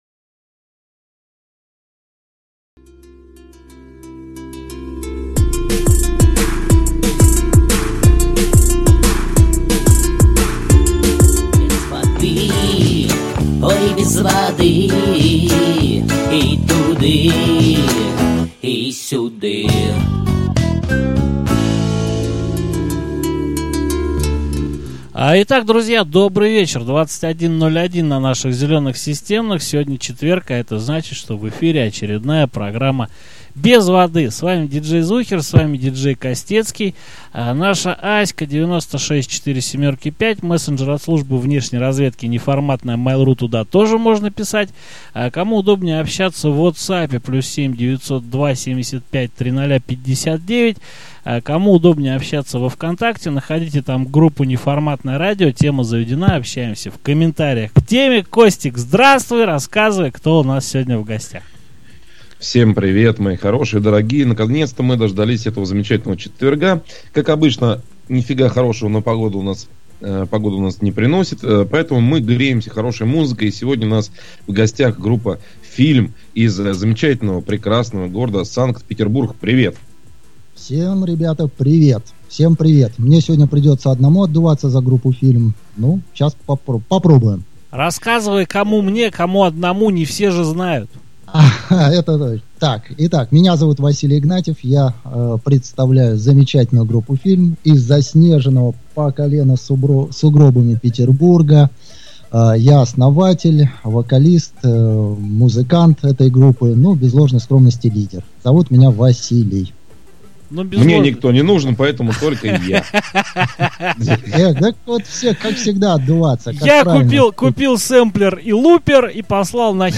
Прозвучали треки, ранее не попадавшие в плейлист Неформатки. Прошли по всем слоям группы, от самого становления и до сегодняшних дней.